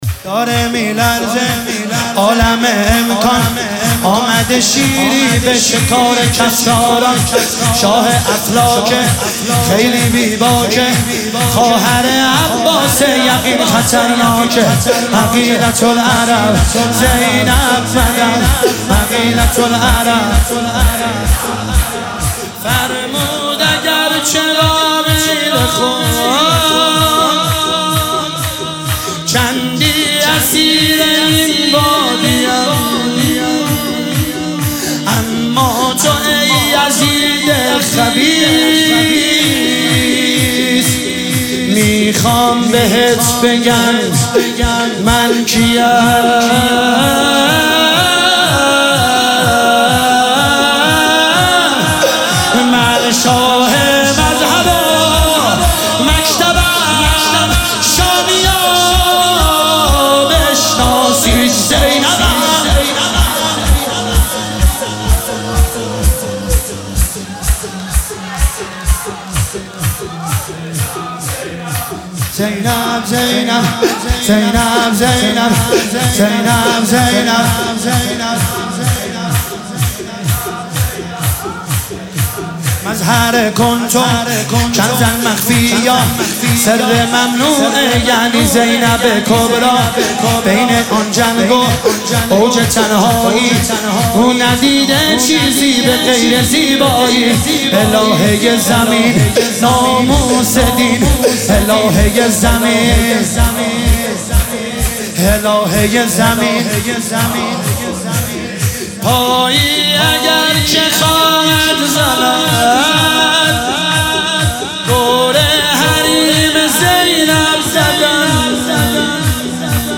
مداحی شور